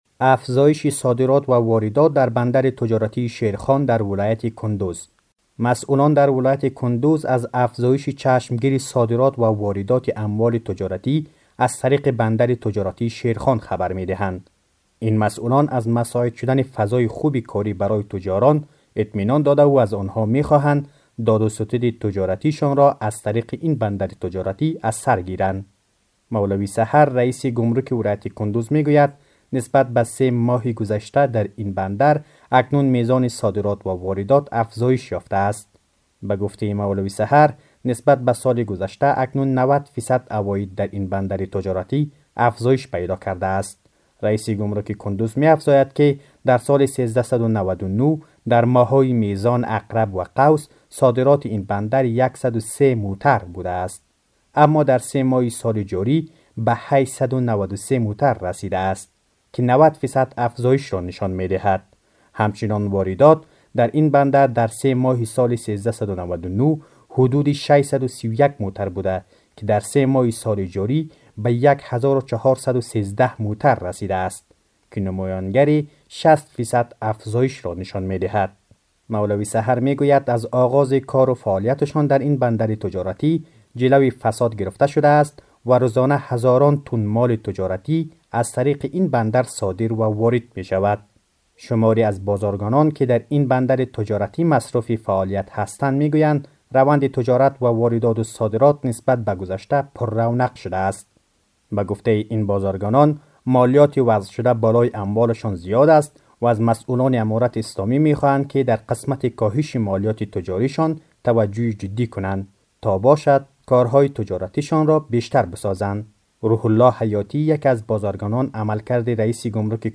به گزارش خبرنگار رادیو دری، این مسئولان از مساعد شدن بستر برای تاجران اطمینان داده و از آنها می‌خواهند، تا داد و ستد تجارتی شان را از طریق این بندر، از سرگیرند.